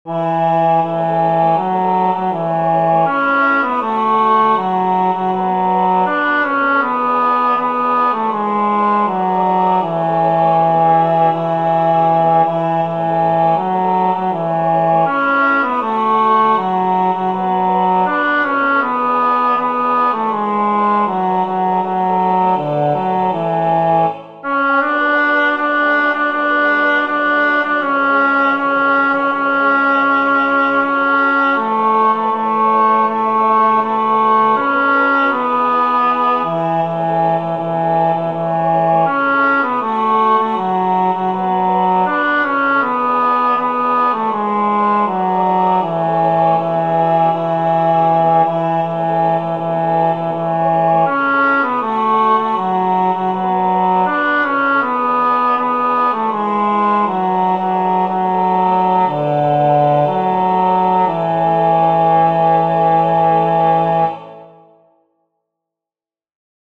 Esta canción popular tiene una estructura muy sencilla, tipo A-B-A’.
El tempo aparece indicado como Moderato.
o-sari-mares-tenor.mp3